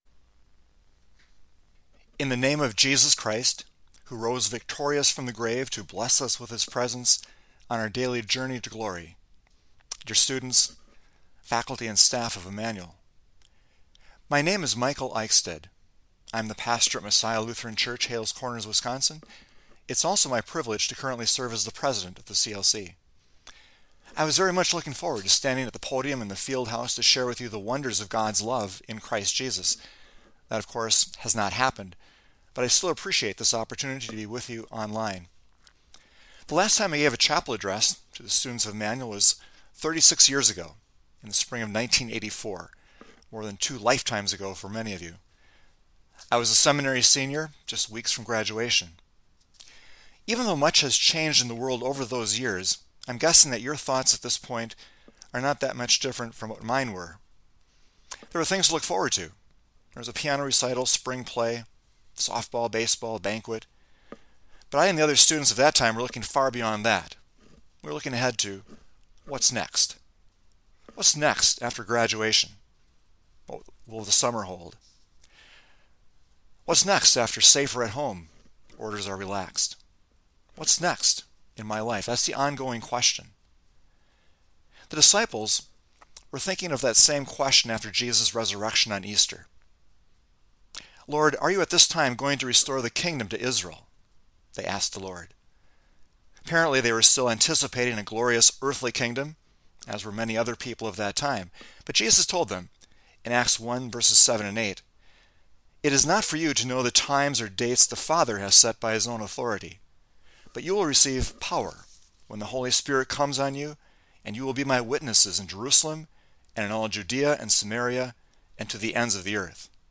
2020-04-20 ILC Chapel — What’s Next?